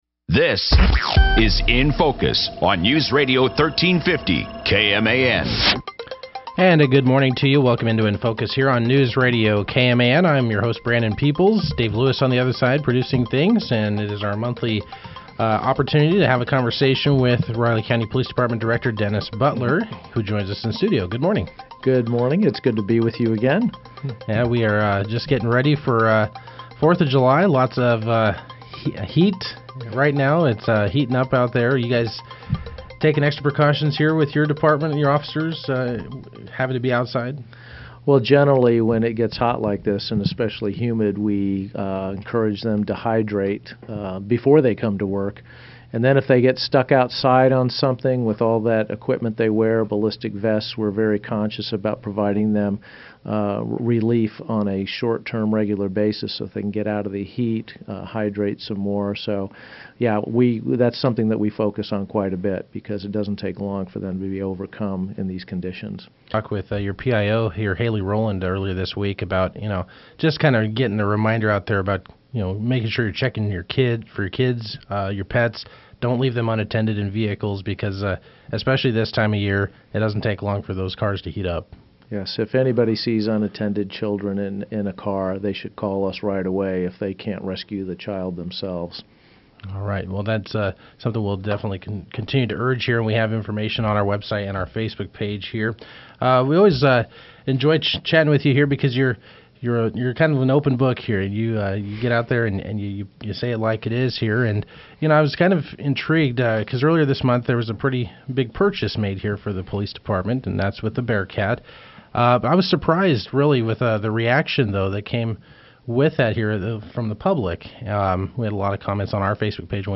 Riley County Police Department Director Dennis Butler was our guest Friday. Butler discussed details of the department’s new purchase of a Bearcat armored vehicle and why it’s necessary for officers to have that level of protection as well as RCPD’s new policy of no longer printing victim’s names in the daily incident reports.